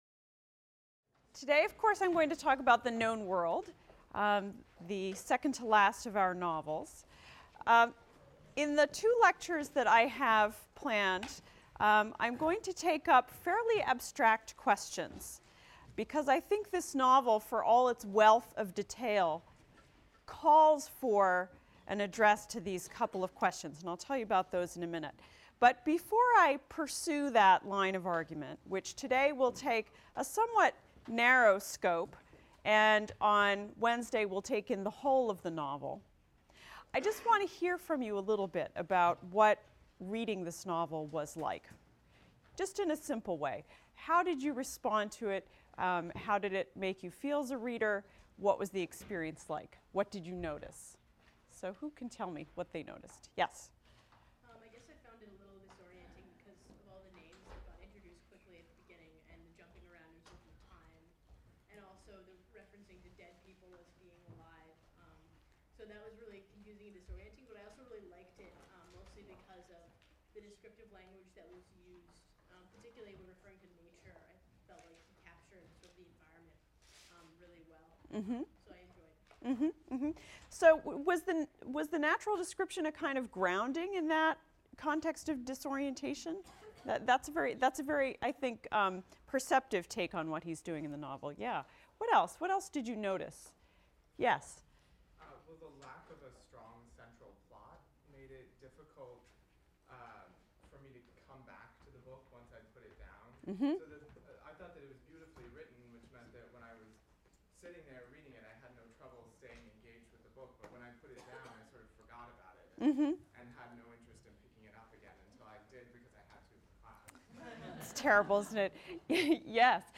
ENGL 291 - Lecture 22 - Edward P. Jones, The Known World | Open Yale Courses